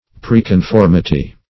Search Result for " preconformity" : The Collaborative International Dictionary of English v.0.48: Preconformity \Pre`con*form"i*ty\, n. Anticipative or antecedent conformity.